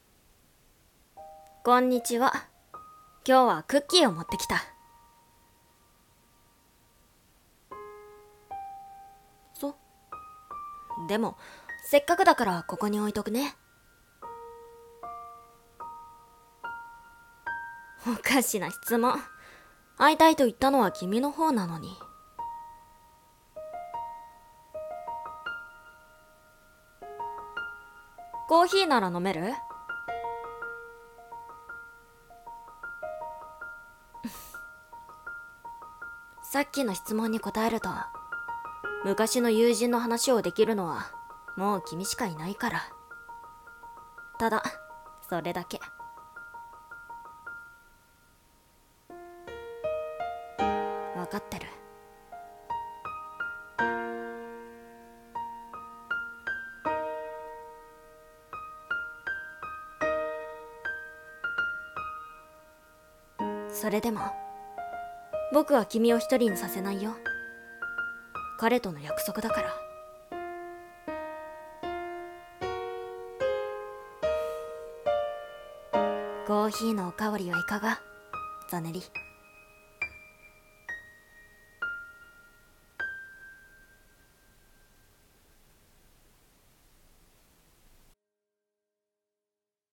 二人声劇【おかわりはいかが？】